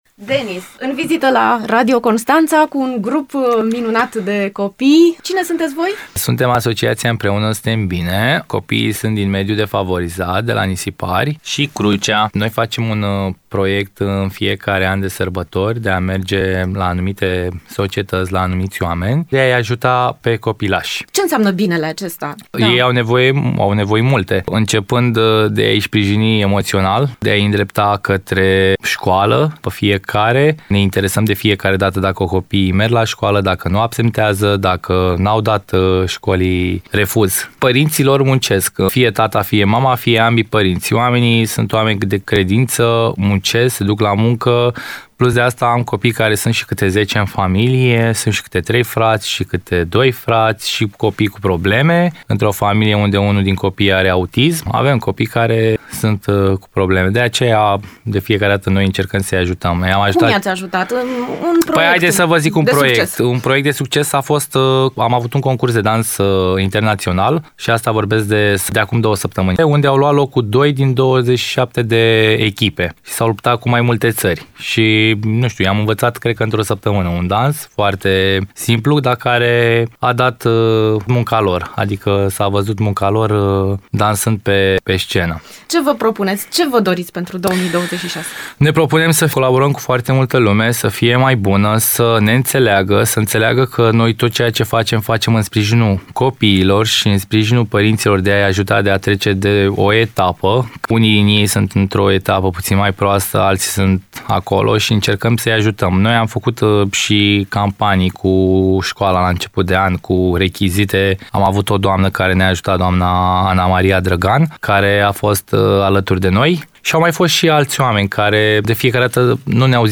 AUDIO | Copiii de la corul „Miracol” au mers cu colindul la Radio Constanța
I-am primit cu drag și la Radio Constanța.